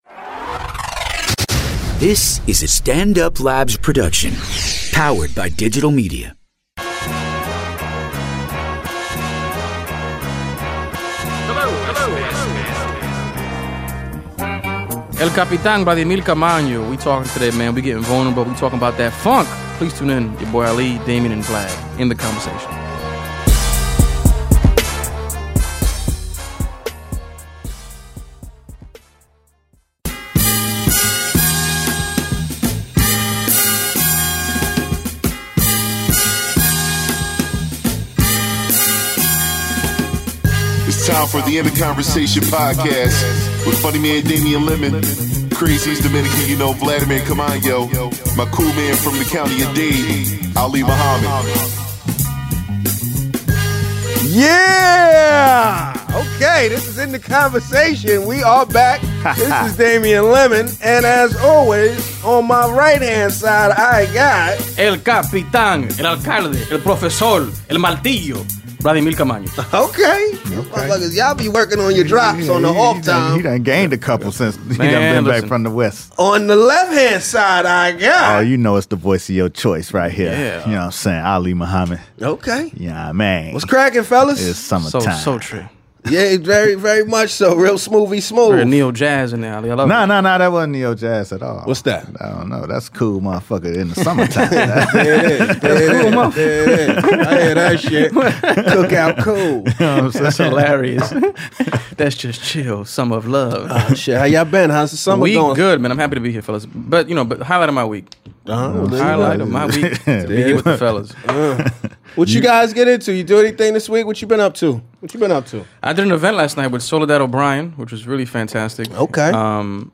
back in-studio together